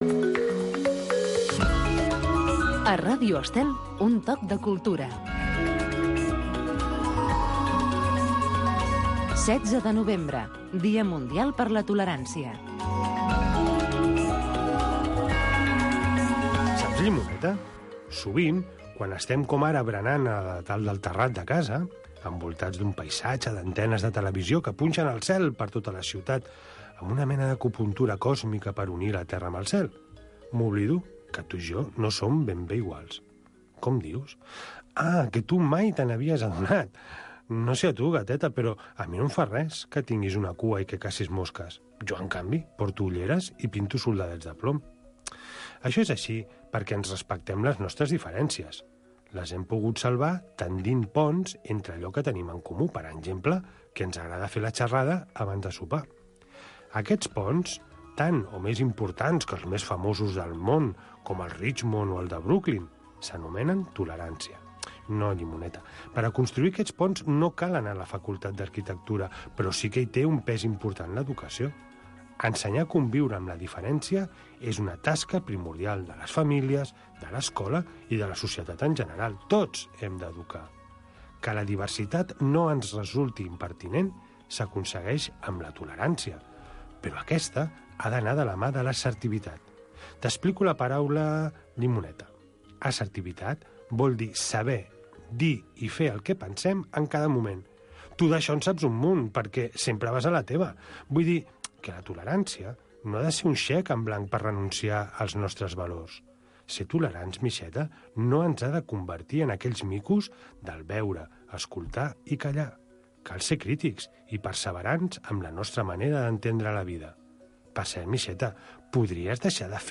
Rutes x Catalunya. Tradicions, festes, cultura, rutes, combinat amb entrevistes i concurs per guanyar molts premis.